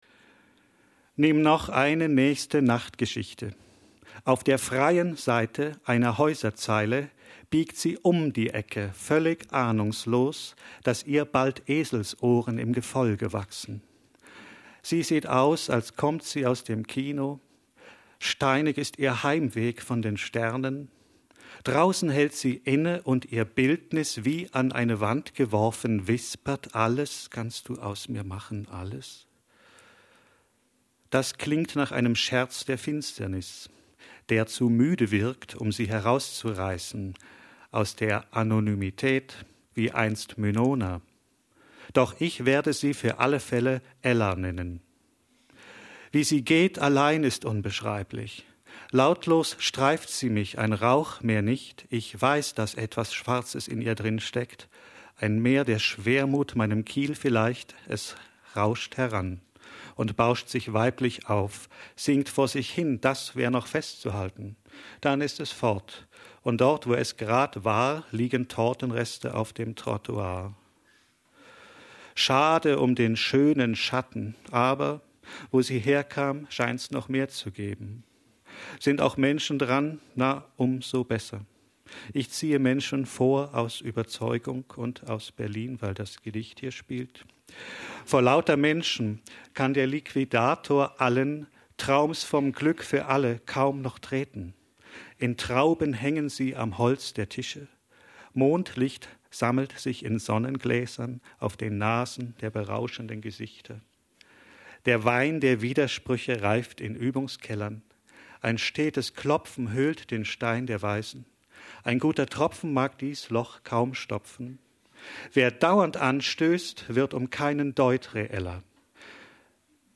Lesung
1998 in der literaturWERKstatt Berlin zur Sommernacht der Lyrik – Gedichte von heute